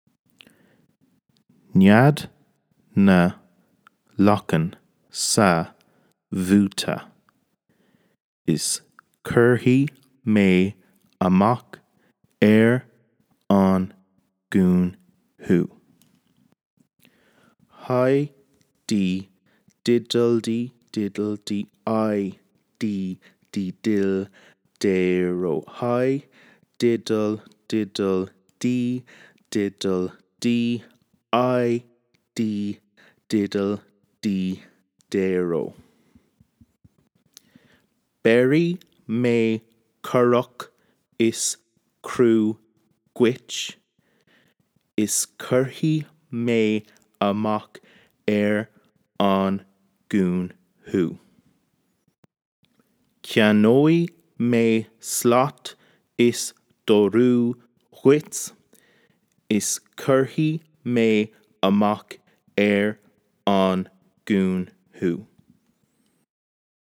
Pronunciation MP3 :
SBMP1881_Pronuciation.mp3